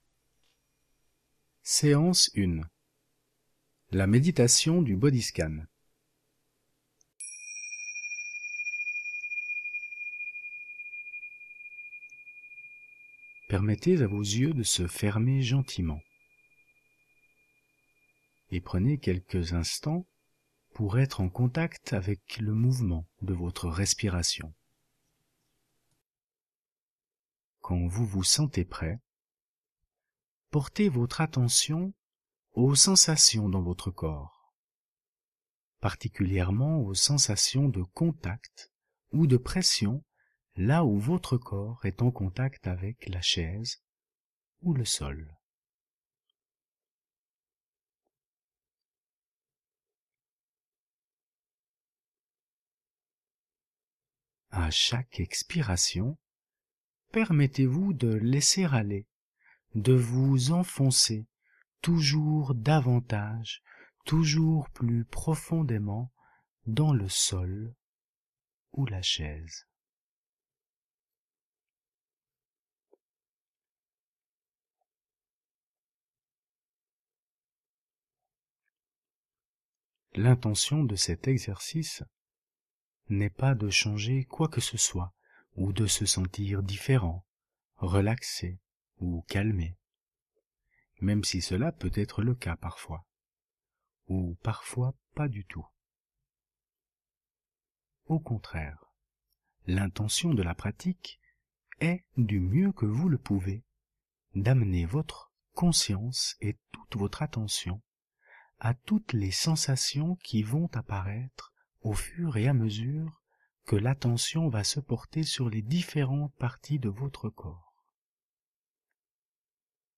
Méditation de Pleine Conscience, pour s'ancrer et se stabiliser … "Le Bodyscan"